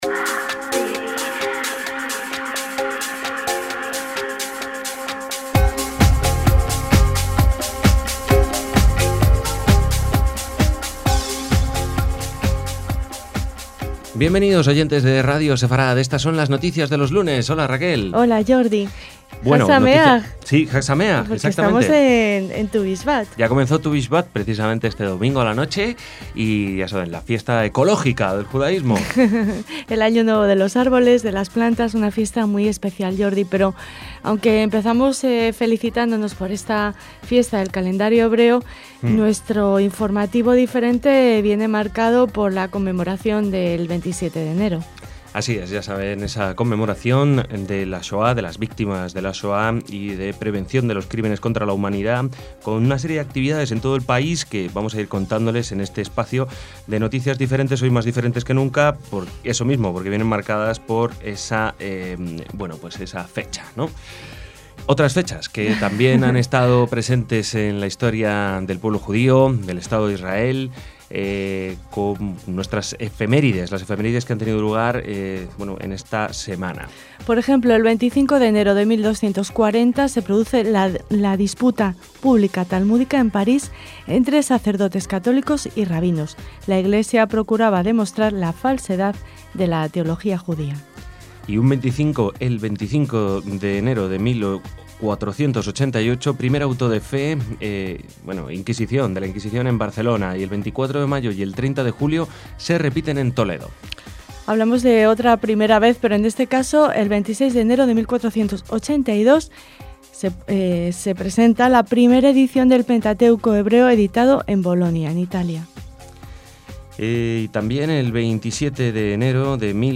LAS NOTICIAS DE LOS LUNES